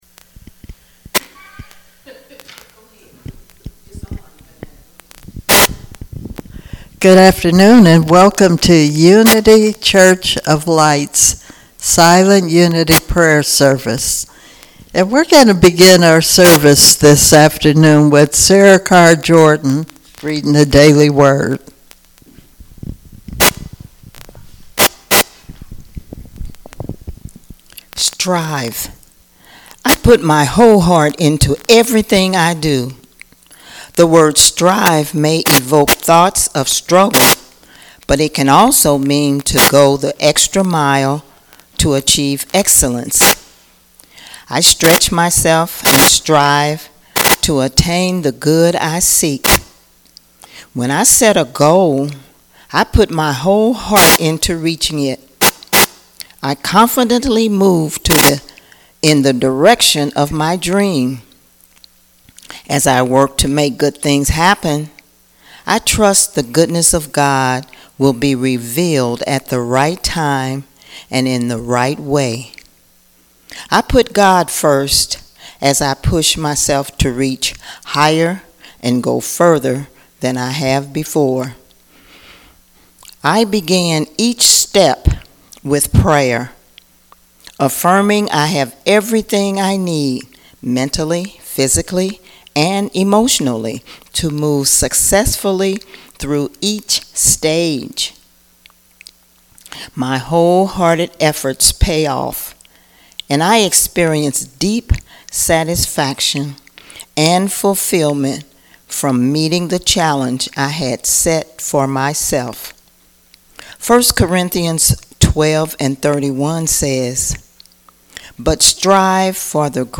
06/07/2023 - Silent Unity Prayer Service
June-7-2023-Silent-Unity-Prayer-Service.mp3